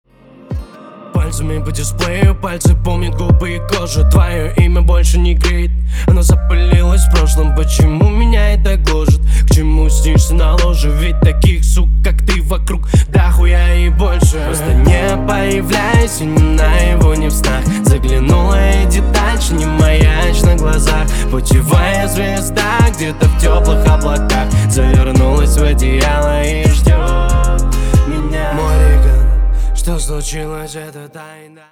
• Качество: 320, Stereo
громкие
лирика
Хип-хоп